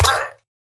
mob / villager / death / haggle.ogg
haggle.ogg